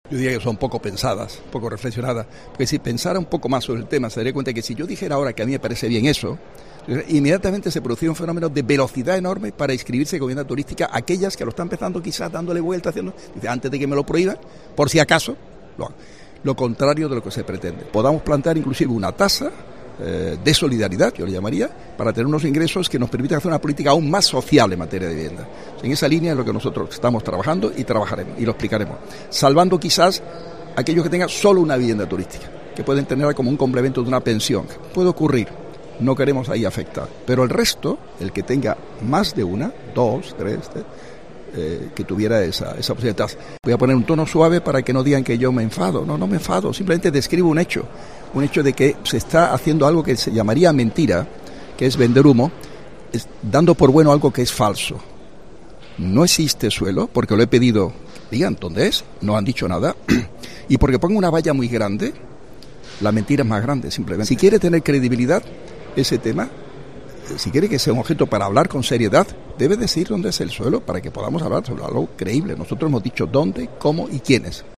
De la Torre asegura a los micrófonos de COPE Málaga que son medidas poco pensadas y que, si el Ayuntamiento de Málaga se abriera a esa posibilidad, provocaría un efecto no deseado: “Si yo dijera ahora que me parece bien eso, inmediatamente se produciría un fenómeno de velocidad enorme para inscribirse como vivienda turística en aquellos que se lo están pensando, lo contrario de lo que se pretende”.